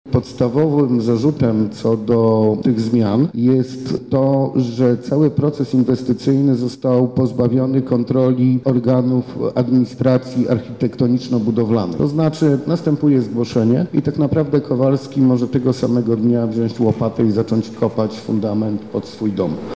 -mówi Przewodniczący Zarządu Związku Gmin Warmińsko-Mazurskich, Wójt Gminy Jonkowo Wojciech Giecko.